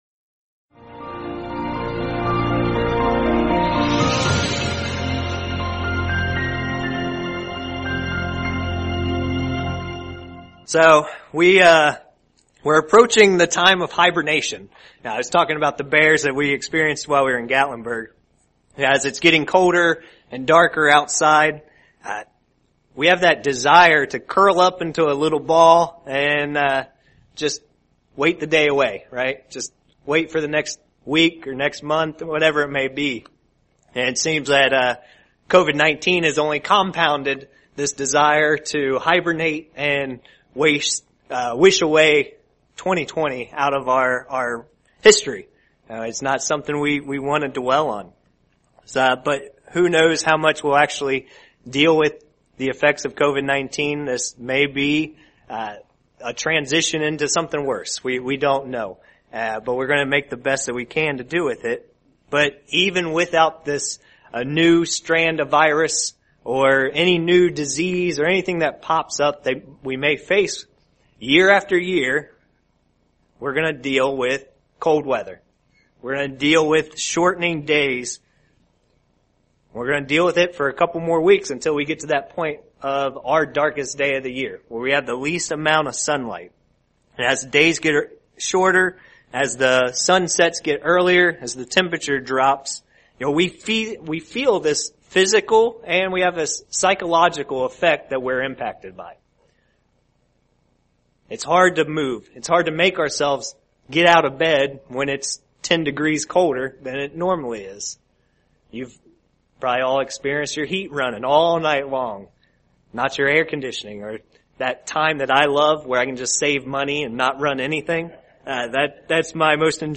Sermons
Given in Indianapolis, IN